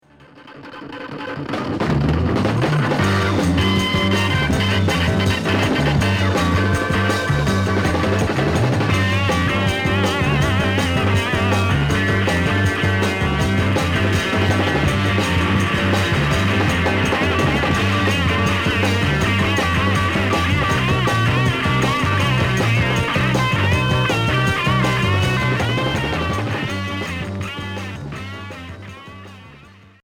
Heavy rock Progressif Deuxième 45t retour à l'accueil